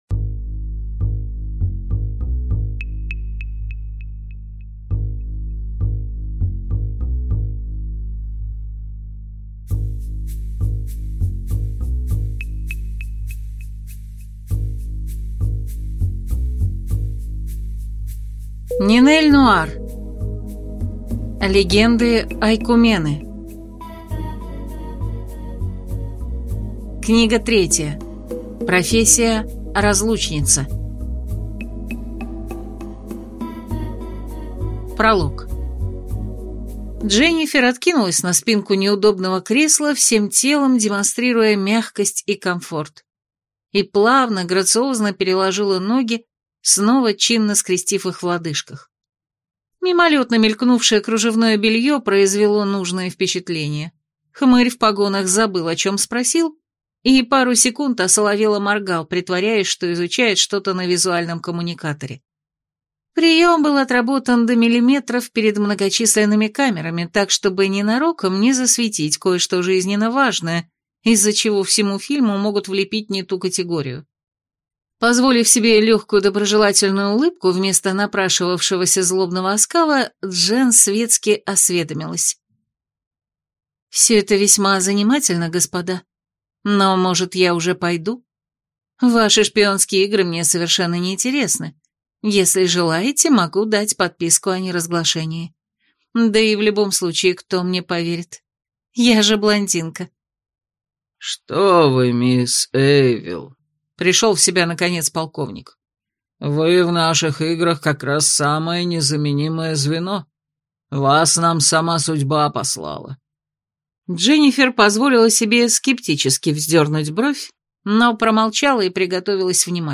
Аудиокнига Легенды Ойкумены. Книга 3. Профессия: разлучница | Библиотека аудиокниг